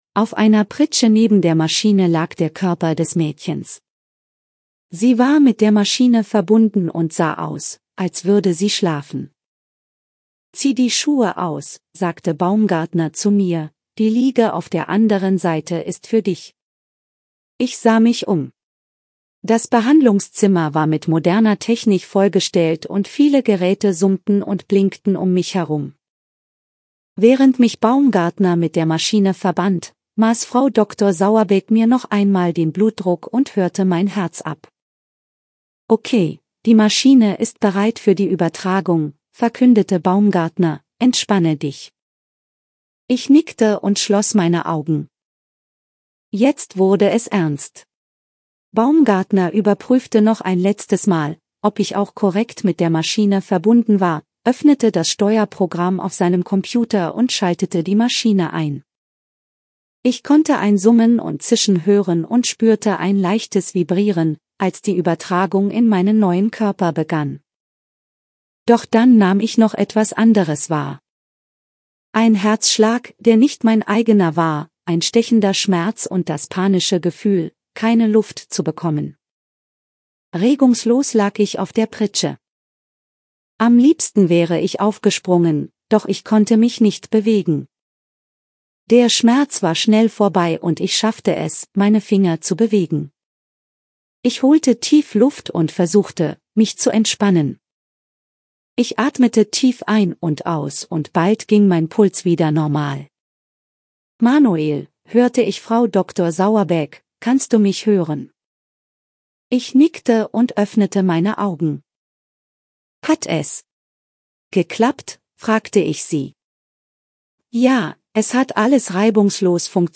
Diesen Text habe ich nun von verschiedenen KI-Werkzeugen lesen lassen.
Und weil alle guten Dinge (heute ausnahmsweise) vier sind, noch ein Text-To-Speech-Konverter: NaturalReaders mit der Stimmen Elke.
naturalreaders_Elke.mp3